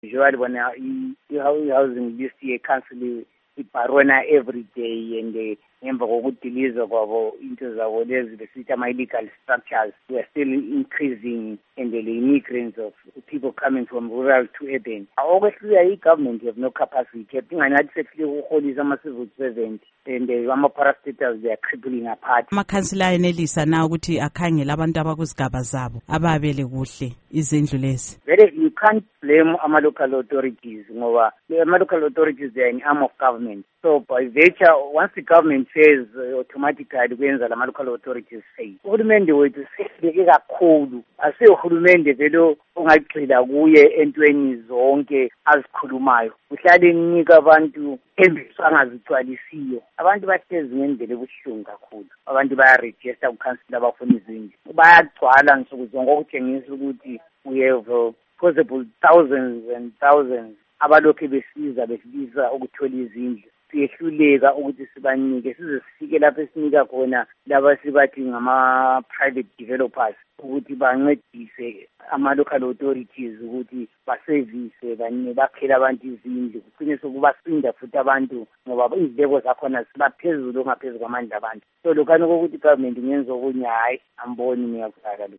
Ingxoxo LoAlderman Charles Mpofu